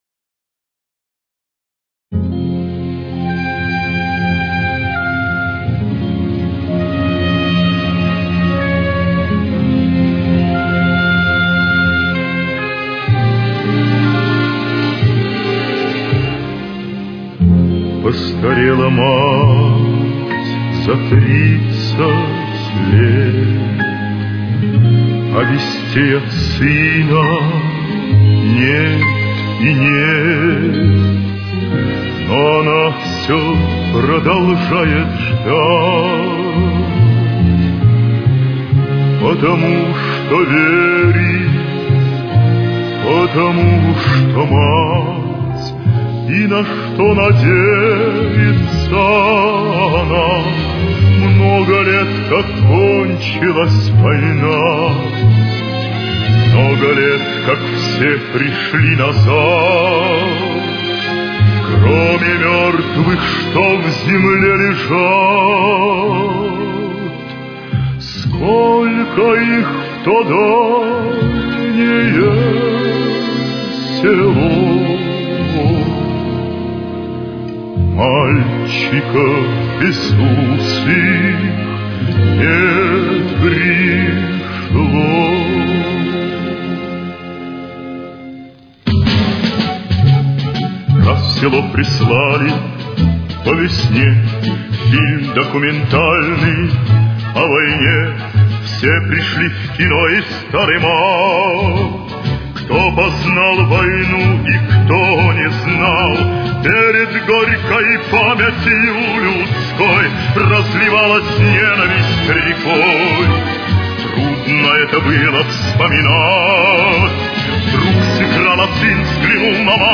Фа минор.